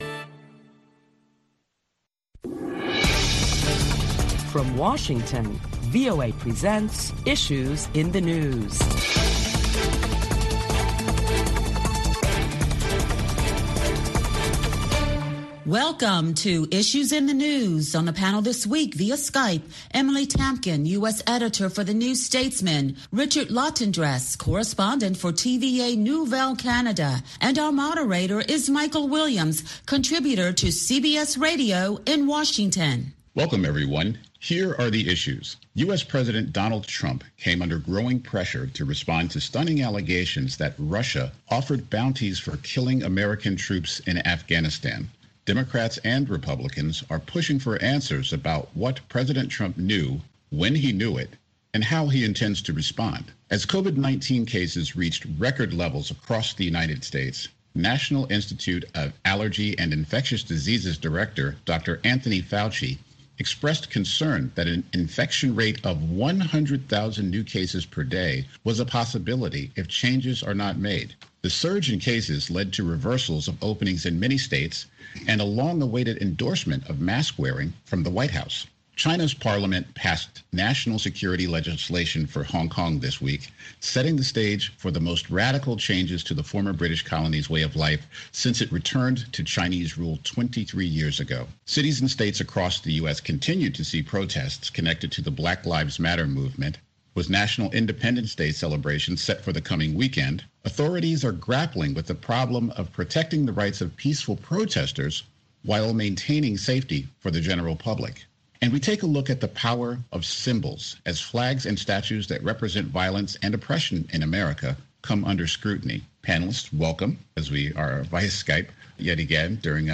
Listen a panel of prominent Washington journalists as they deliberate the latest top stories of the week including the growing pressure for President Trump to respond to allegations that Russia offered bounties for killing American troops in Afghanistan.